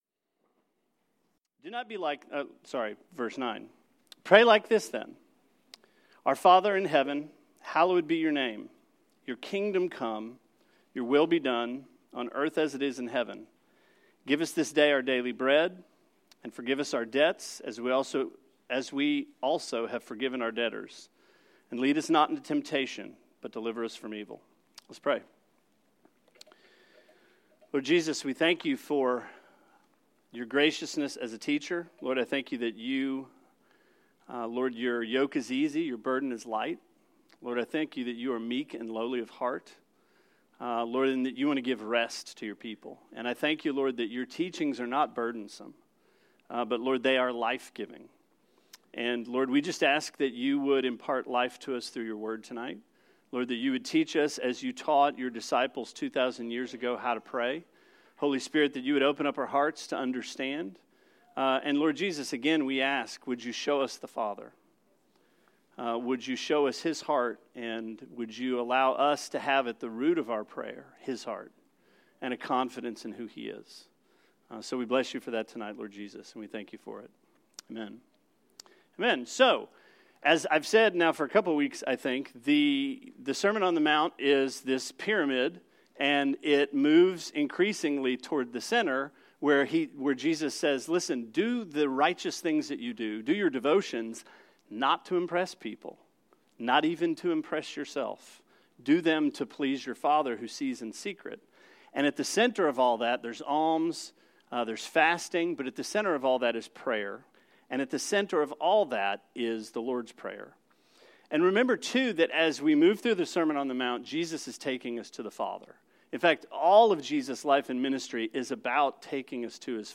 Sermon 02/28: The Lord’s Prayer in Six Petitions – Part One